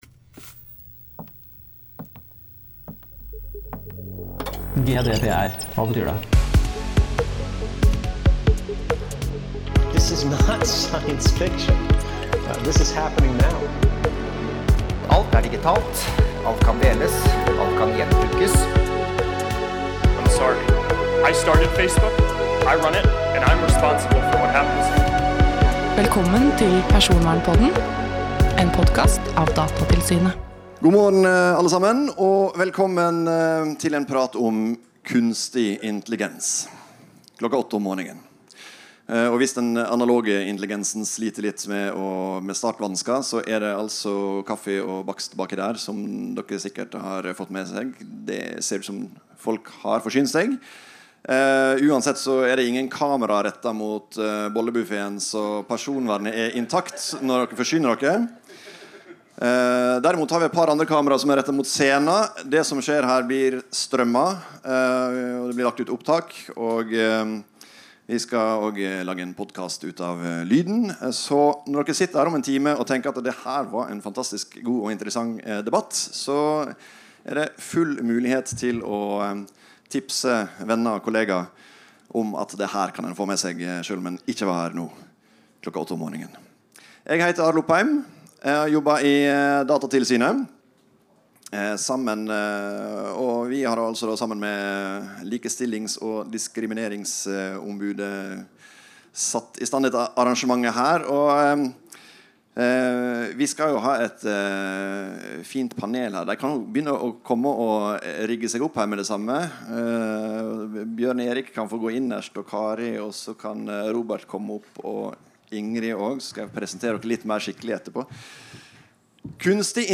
Dette er et opptak fra arrangementet.
live_nar-algoritmer-saksbehandler.mp3.mp3